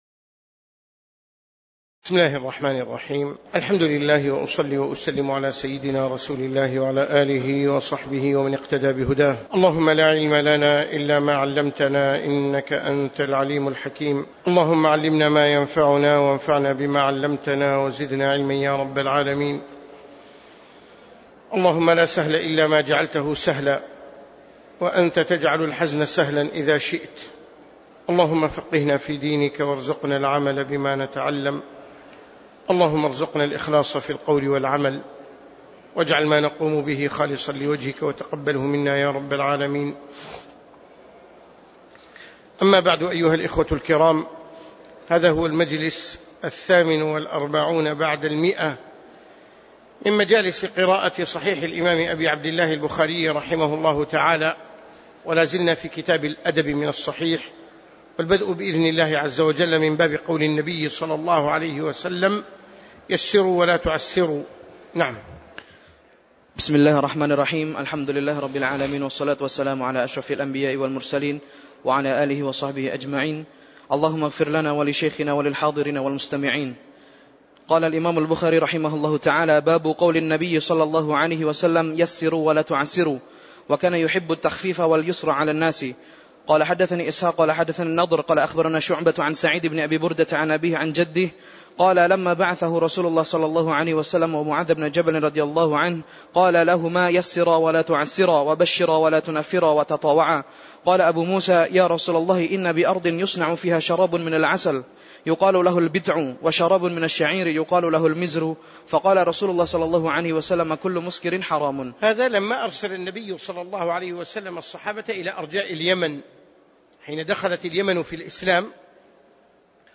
تاريخ النشر ١٨ محرم ١٤٣٩ هـ المكان: المسجد النبوي الشيخ